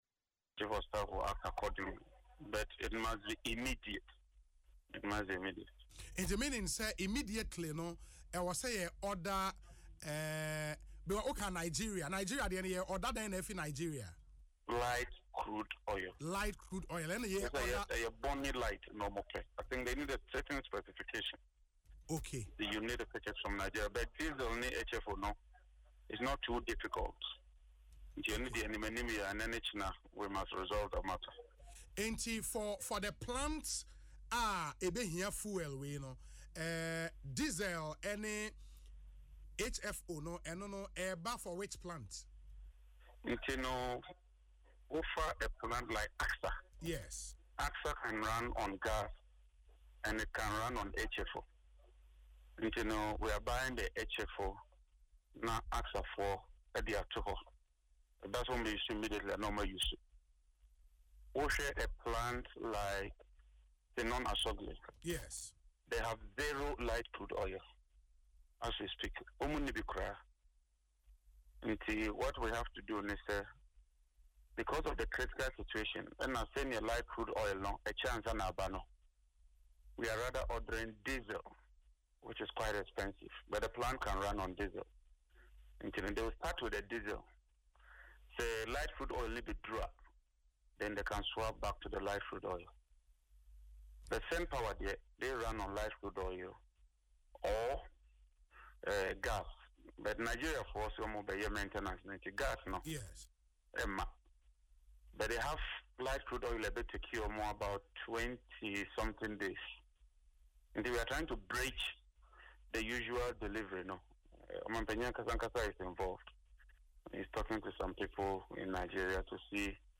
Yapei-Kusawgu Member of Parliament(MP) John Jinapor disclosed this on Asempa FM Ekosii Sen, Wednesday.